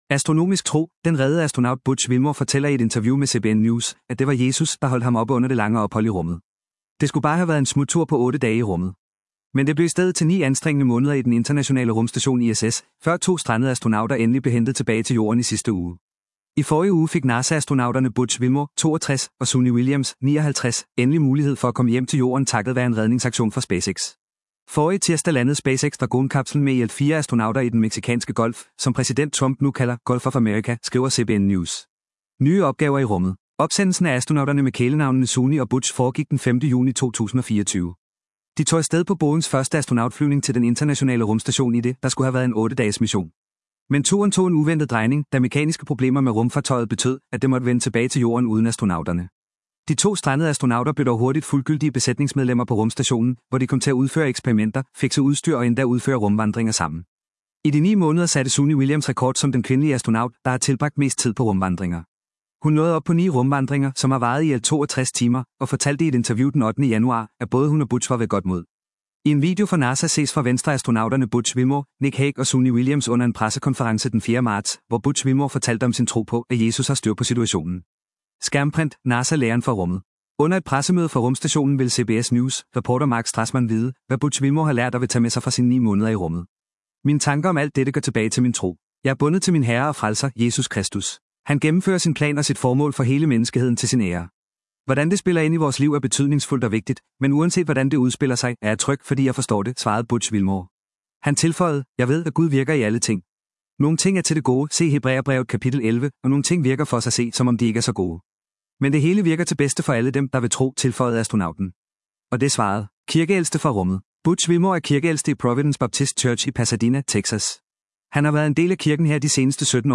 Astronomisk tro: Den reddede astronaut Butch Wilmore fortæller i et interview med CBN News, at det var Jesus, der holdt ham oppe under det lange ophold i rummet.